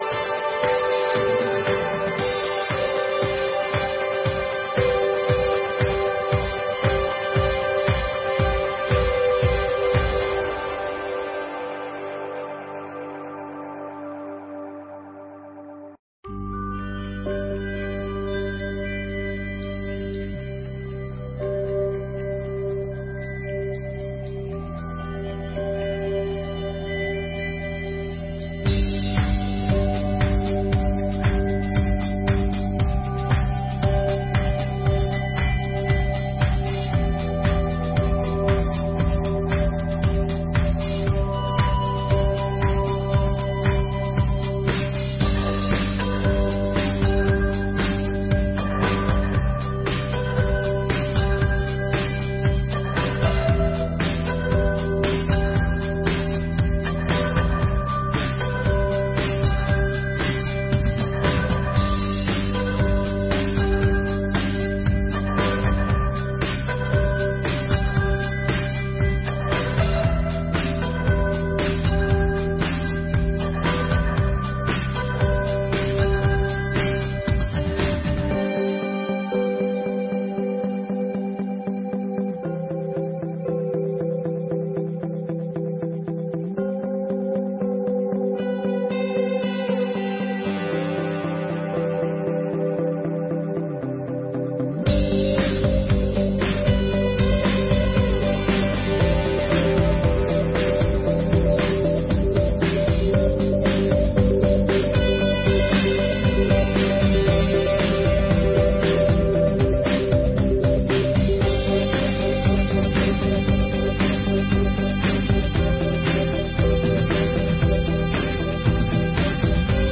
Sessões Solenes de 2024